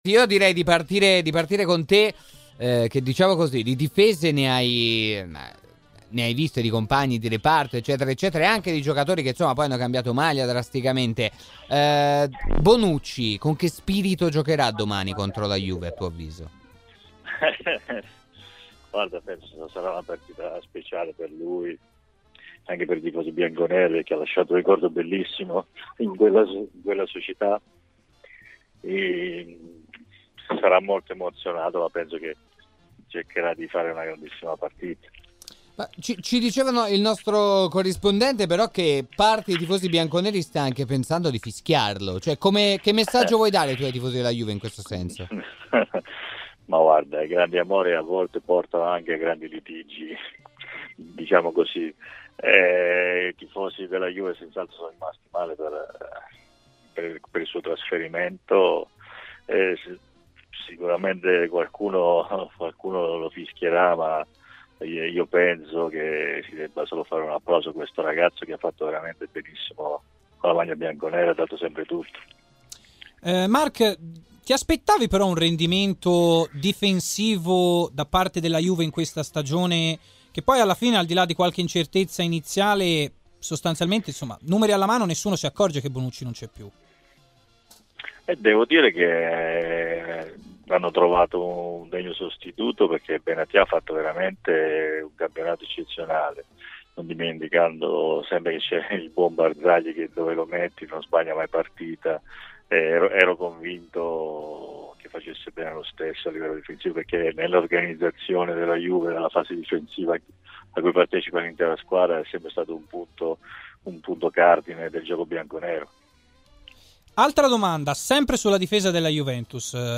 Le dichiarazioni di Mark Iuliano, ex difensore della Juventus, intervenuto nel Live Show serale di RMC Sport ha parlato così di Bonucci: "Per lui quella di sabato sera sarà una partita speciale ed anche per i tifosi bianconeri, perché  ha lasciato un ricordo bellissimo in quella società.
Mark Iuliano, allenatore ed ex giocatore della Juventus, sulla sfida con il Milan e sulle chance Champions a partire dal match con il Real Madrid.